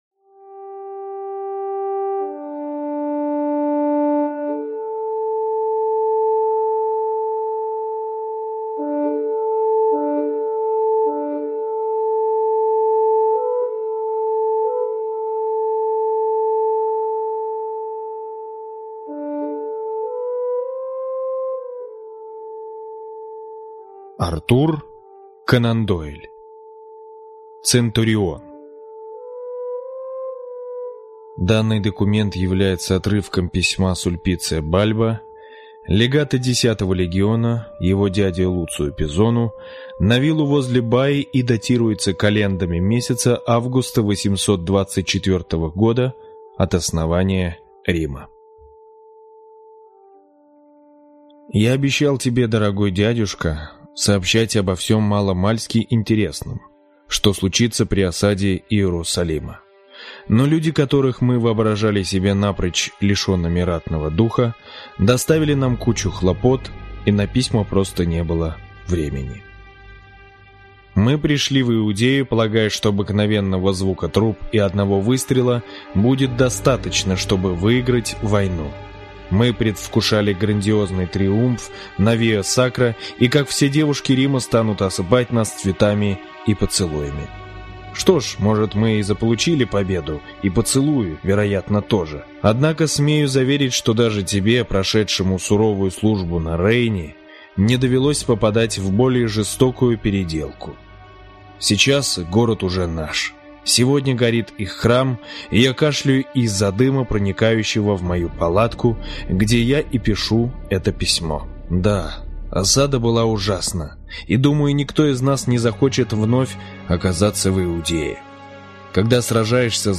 Аудиокнига Литературная мозаика (сборник рассказов) | Библиотека аудиокниг